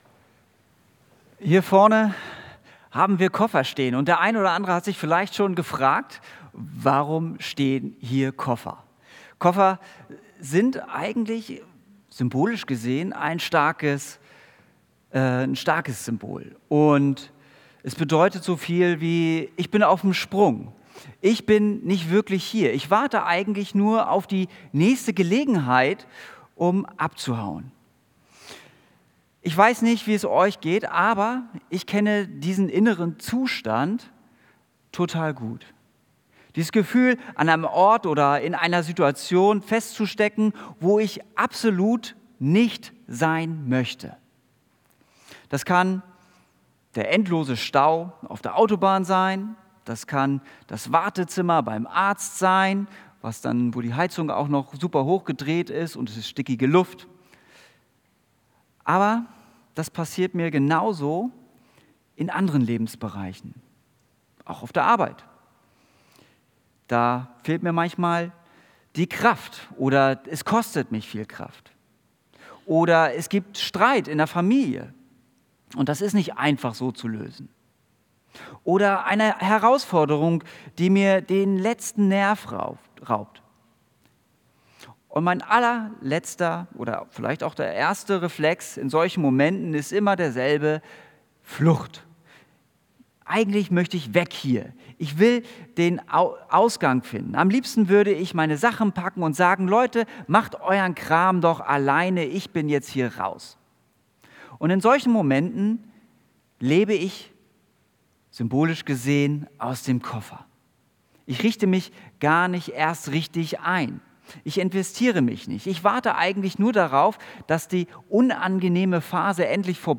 Predigt Gottes neue Wege: Neu anfangen, wo du nicht sein wolltest.